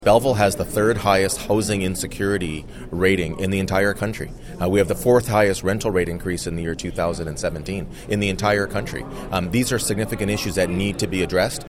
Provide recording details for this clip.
In the media interview, he said, “I think city council certainly had their minds focused on the challenges and opportunities and tomorrow (Tuesday) I’m looking forward to something concrete to come out as recommendations.”